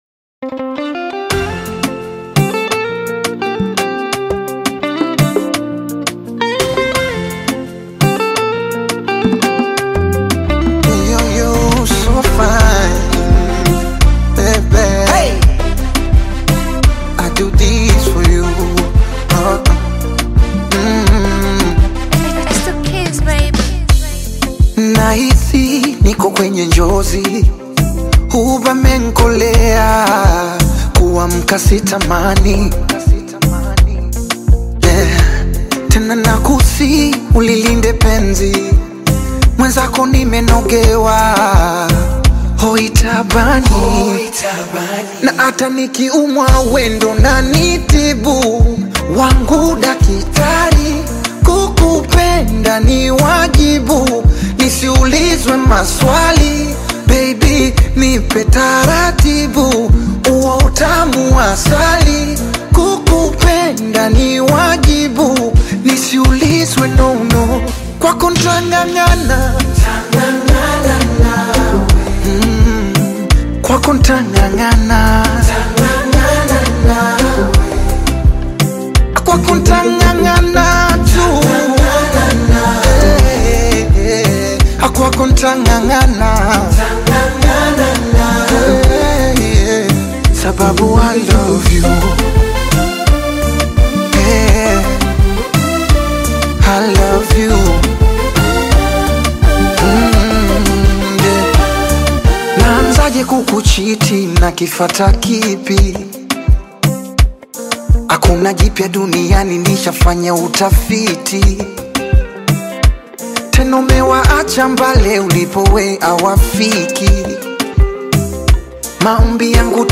soulful and emotive
smooth vocals